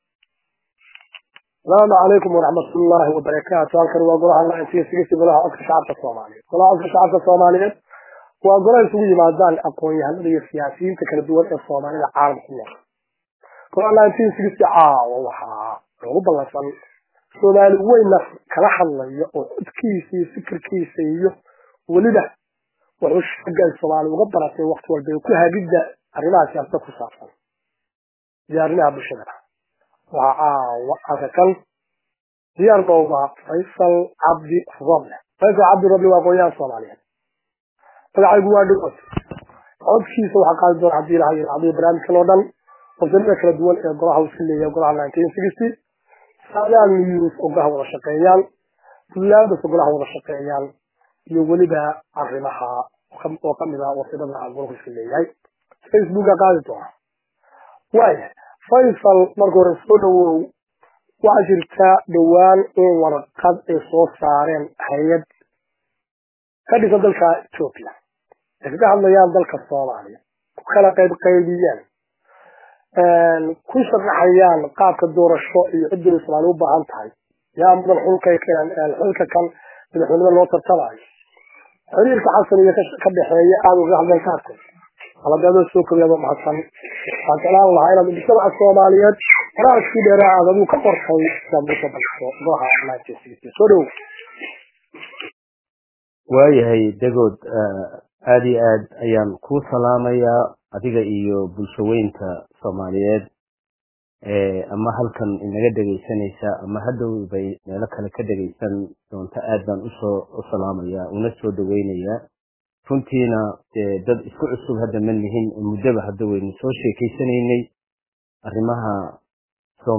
waraysi